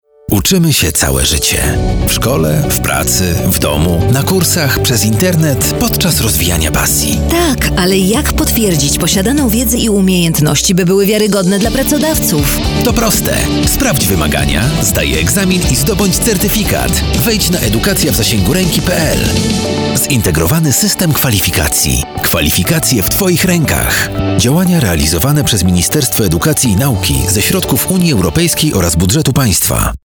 Zintegrowany System Kwalifikacji – spot radiowy
Zintegrowany-system-kwalifikacji-spot-radiowy.mp3